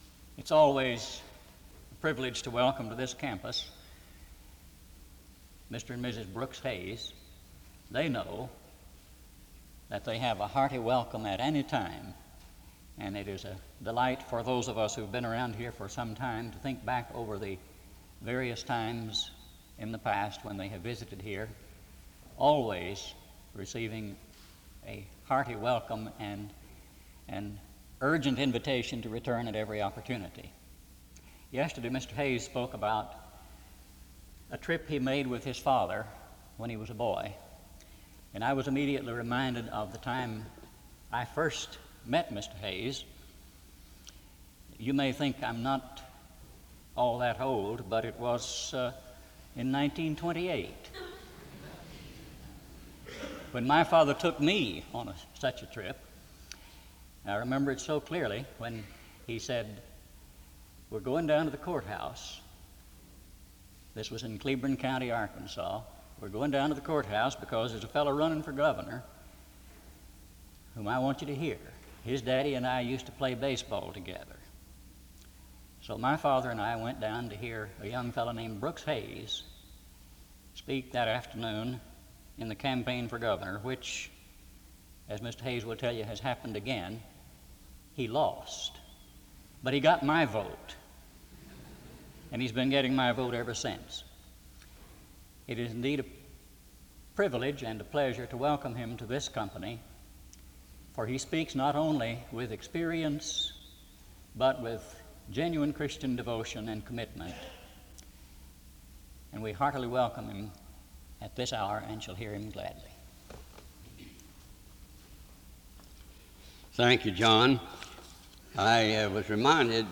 SEBTS Fall Lecture - Brooks Hays October 13, 1976
Última modificación 02/13/2026 Creator Hays, Brooks Southeastern Baptist Theological Seminary Language English Identifier SEBTS_Fall_Lecture_Brooks_Hays_1976-10-13 Date created 1976-10-13 Location Wake Forest (N.C.)